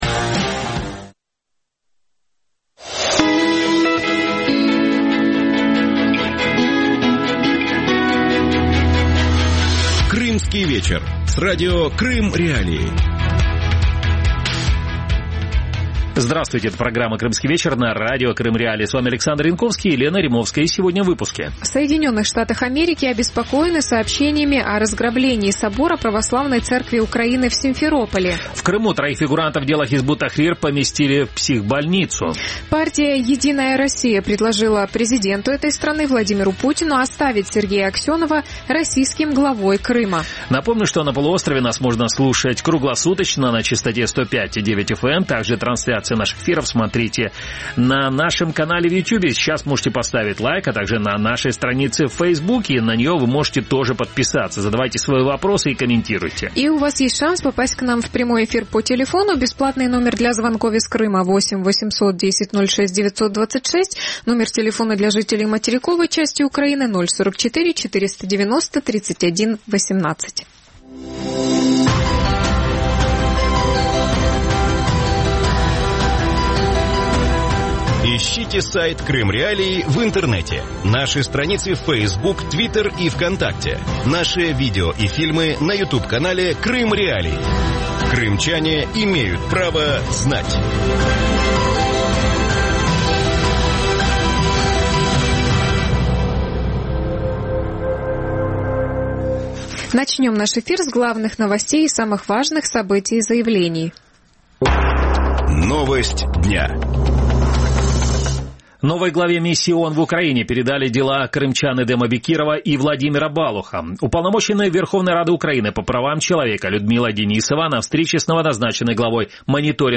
Как компании «Консоль» удалось осуществить строительство в охранной зоне обсерватории? Может ли строительство жилого дома и отеля мешать научным исследованиям в обсерватории? Гости эфира: